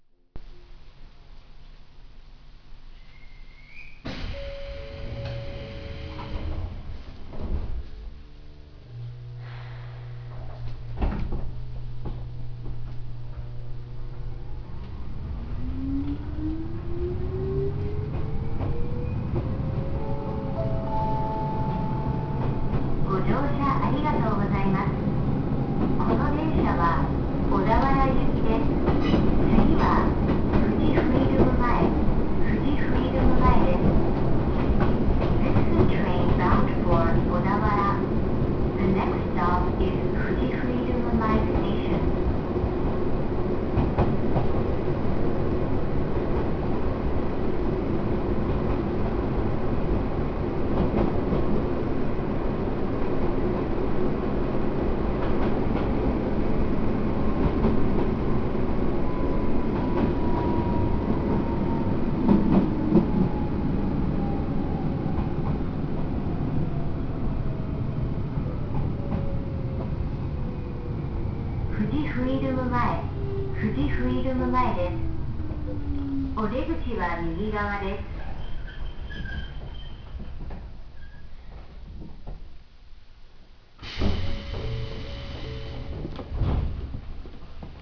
・5000系走行音
【大雄山線】大雄山→富士フィルム前（1分27秒：478KB）
鋼製車もステンレス車も変わらず抵抗制御で、取り立てて特徴のある音ではありません。ドアチャイムは東海道新幹線タイプのチャイムを用いています。自動放送に英語が設けられているのが一応の特徴でしょうか。
大雄山線はいまいち速度を出す区間がないので、何処で録ってもあまり豪快な走行音は録れません。